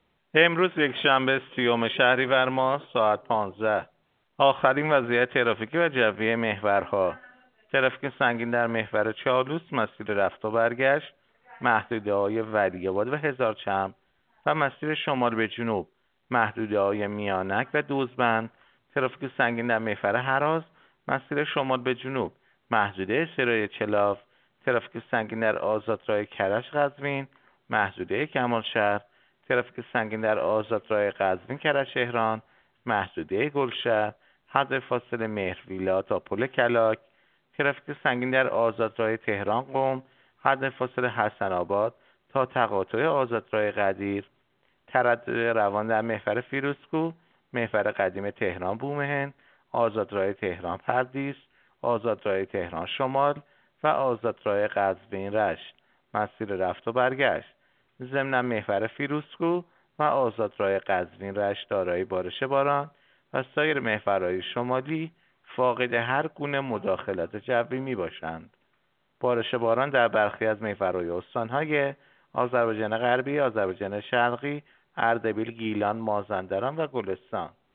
گزارش رادیو اینترنتی از آخرین وضعیت ترافیکی جاده‌ها ساعت ۱۵ سی‌ام شهریور؛